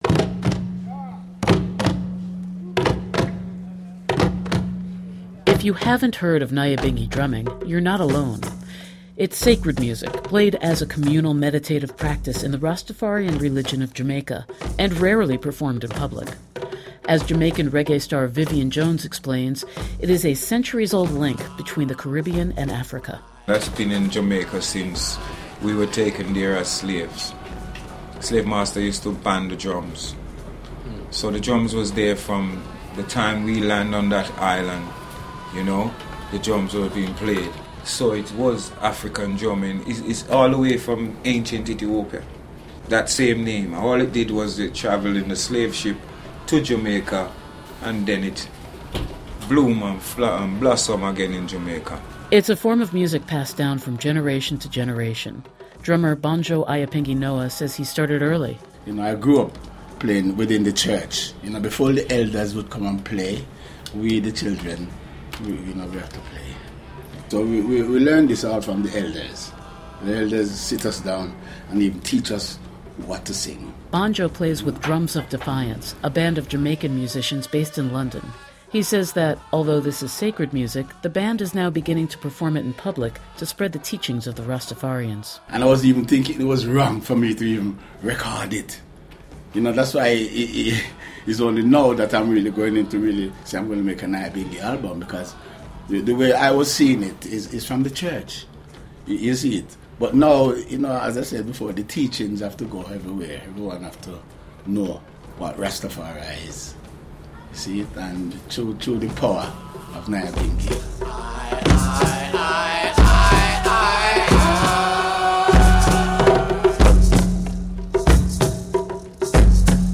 Nyabinghi drumming is sacred music in the Rastafarian religion of Jamaica